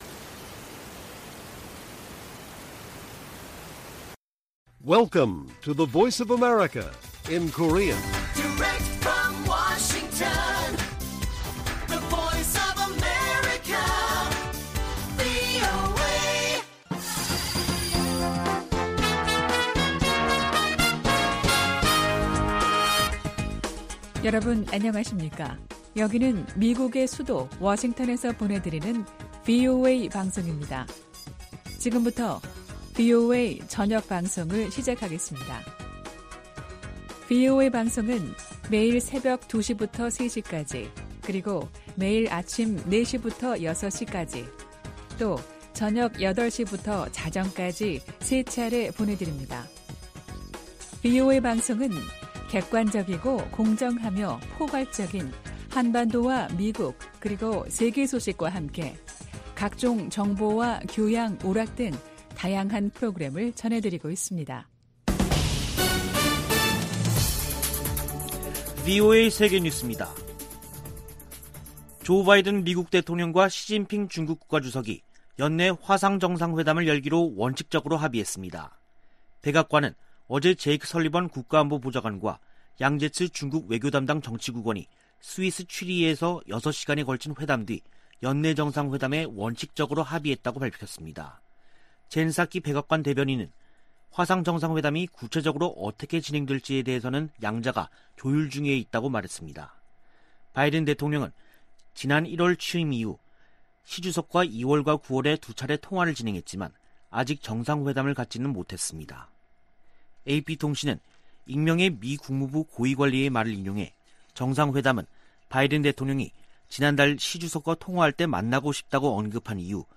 VOA 한국어 간판 뉴스 프로그램 '뉴스 투데이', 2021년 10월 7일 1부 방송입니다. 북한이 영변 핵시설 내 우라늄 농축공장 확장 공사를 계속하고 있는 것으로 파악됐습니다.